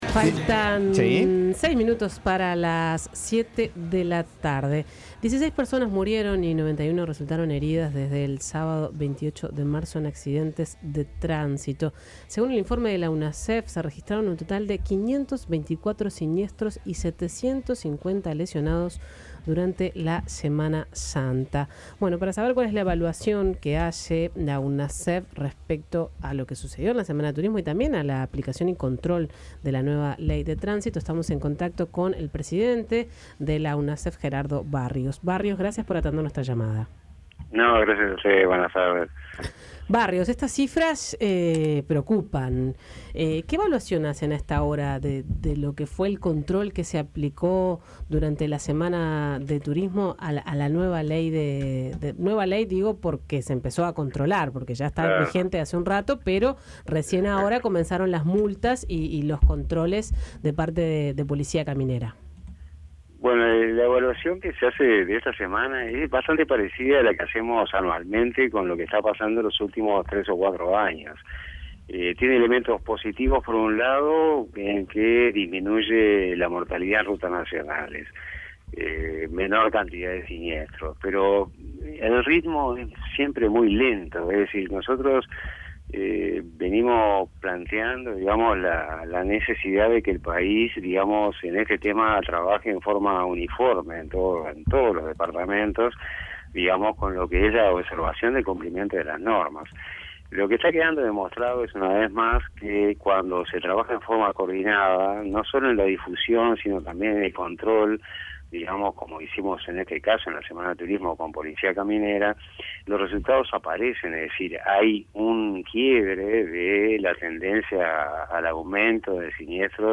Gerardo Barrios, presidente de la Unidad Nacional de Seguridad Vial, conversó con 810 Vivo.